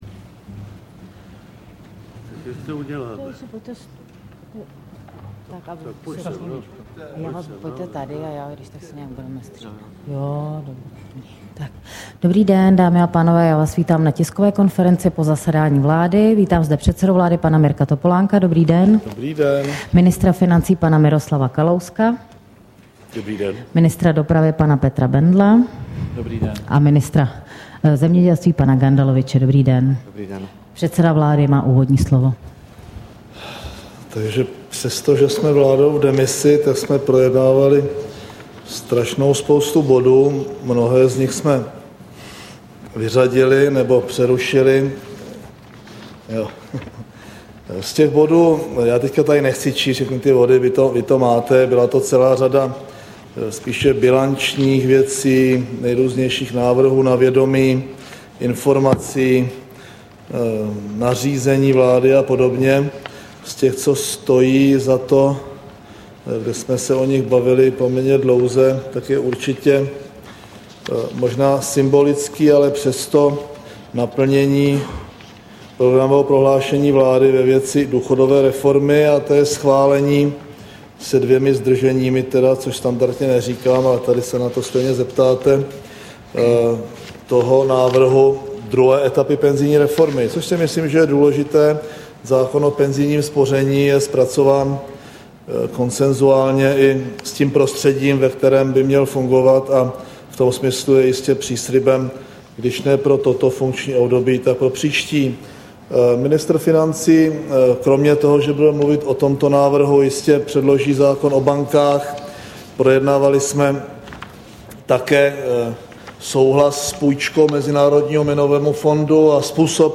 Tisková konference po jednání vlády České republiky, 20. dubna 2009